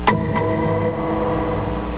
boink.wav (31.8 k)
boink-69187.wav